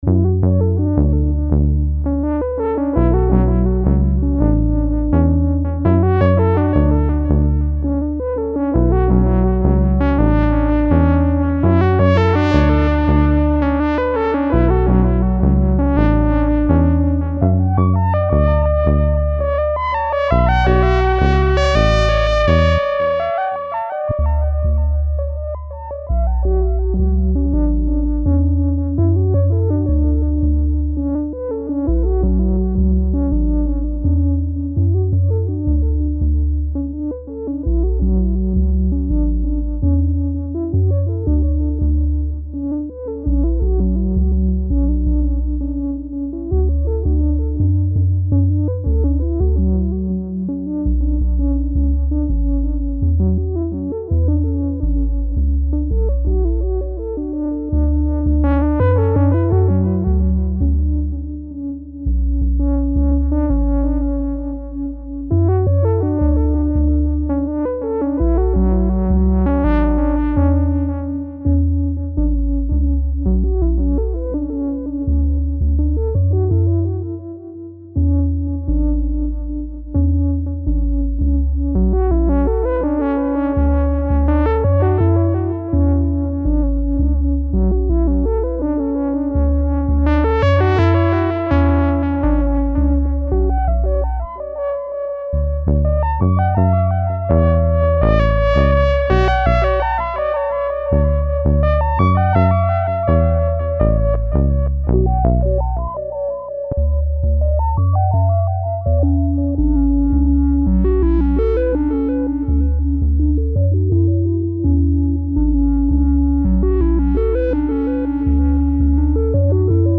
More fun with modular